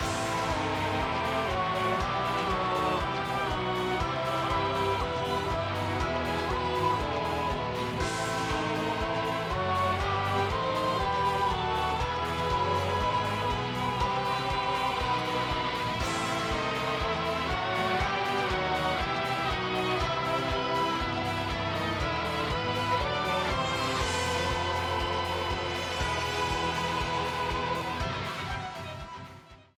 A theme
Ripped from the game
clipped to 30 seconds and applied fade-out